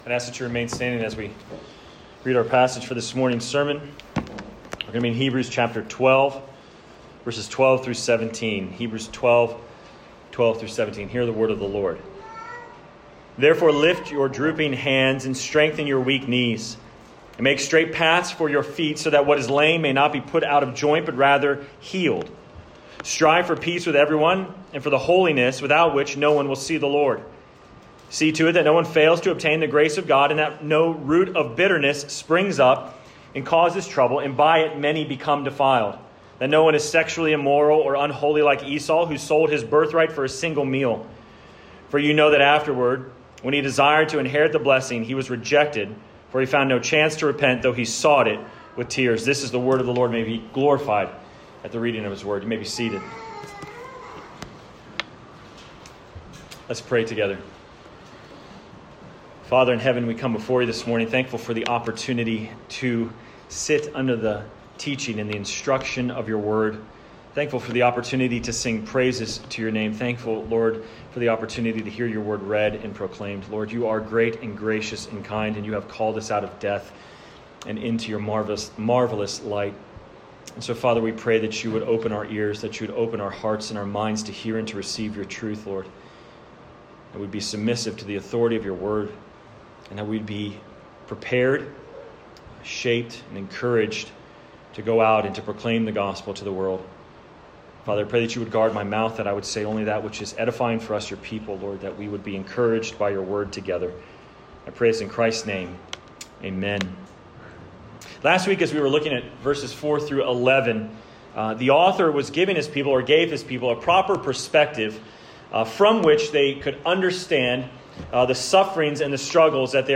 Sermon Text: Hebrews 12:12-17 First Reading: Isaiah 35 Second Reading: Hebrews 3:1-18